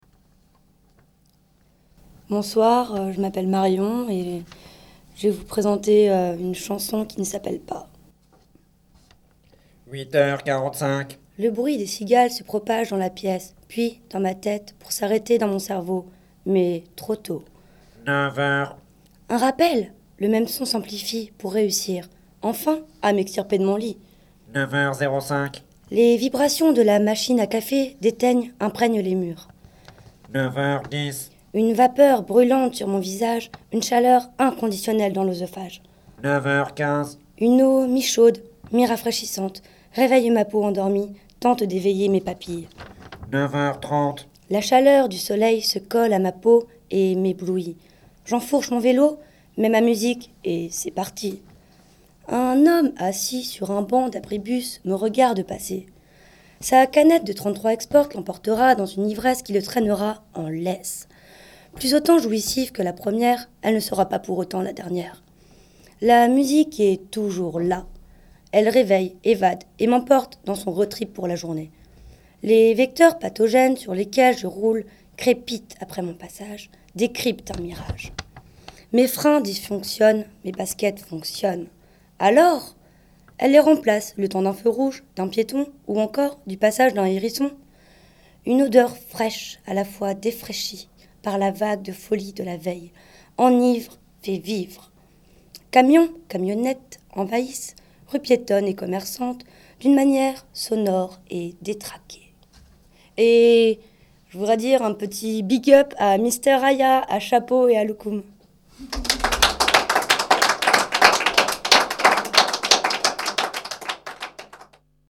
ateliers slam , écriture et enregistrement de séquences
séquence slam 4